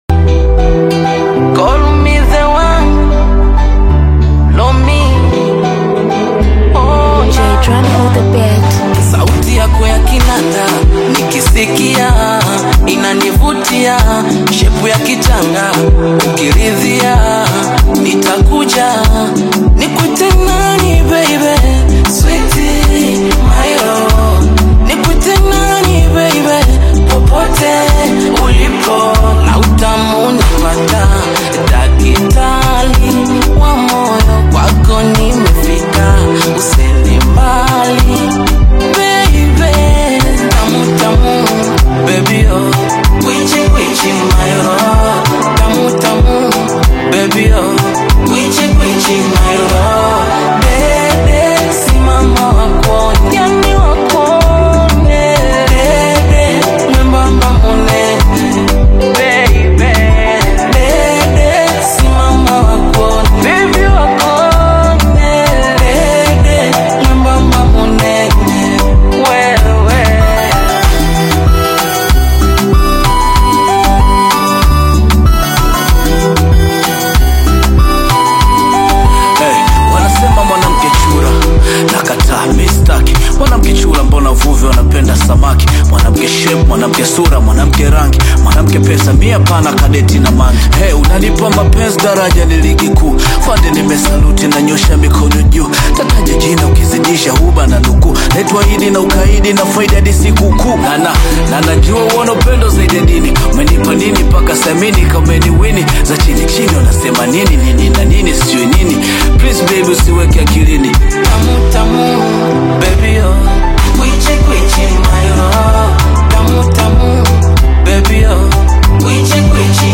AfrobeatAudioBongo flava